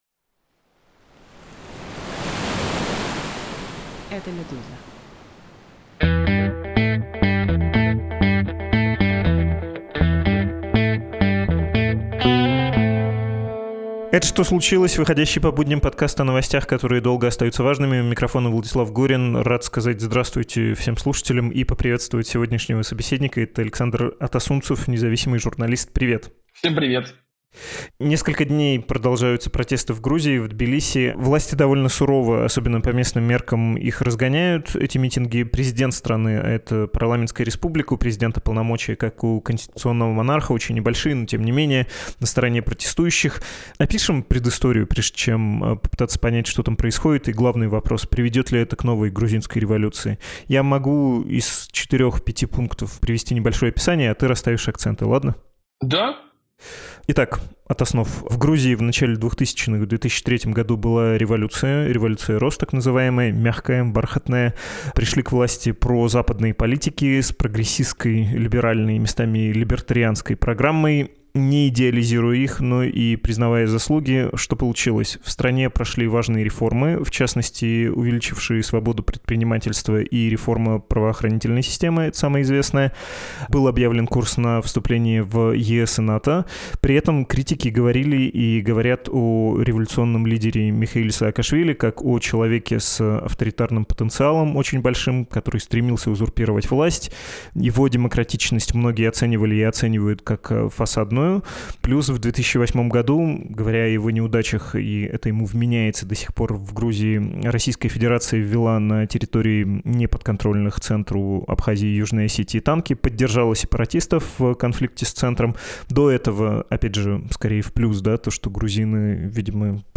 Говорим об этом с независимым журналистом